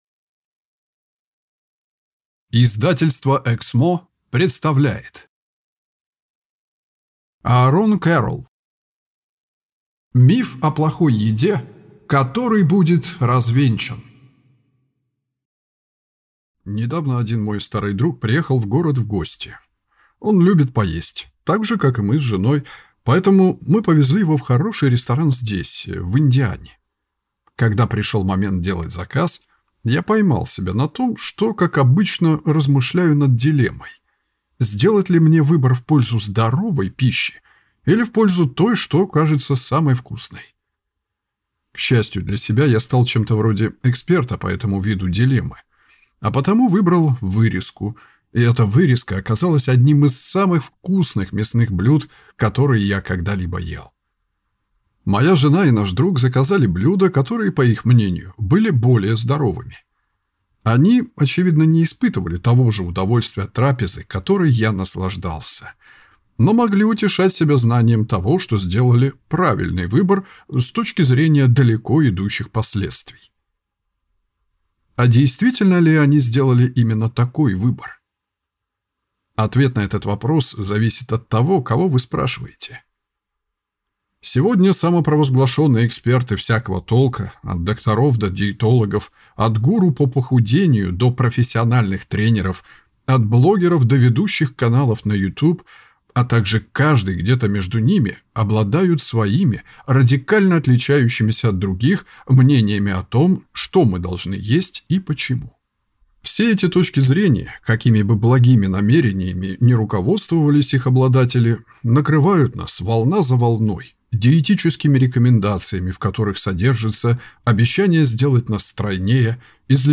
Аудиокнига Миф о плохой еде, который будет развенчан!